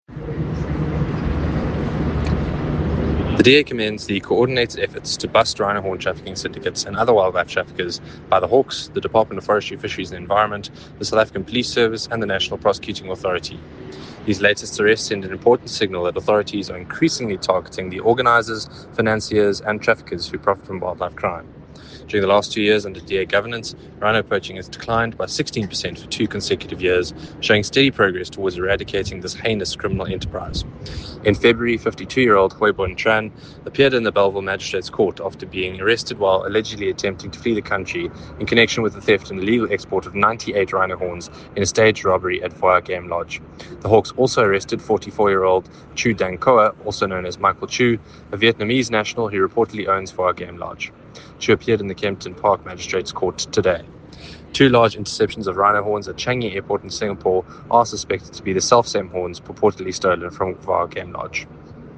soundbite by Andrew de Blocq MP.
Andrew-de-Blocq-MP-English.mp3